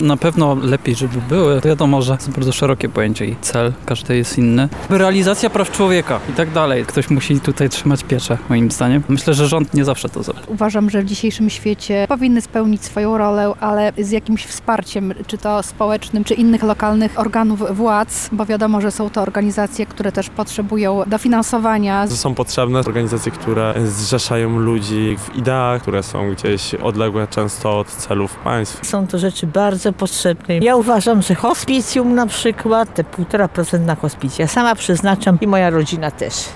Według udostępnionych danych, w Lublinie zarejestrowanych jest ponad 1,5 tysiąca różnych pozarządowych stowarzyszeń. Postanowiliśmy zapytać mieszkańców o zdanie na ich temat:
Światowy Dzień Organizacji Pozarządowych- sonda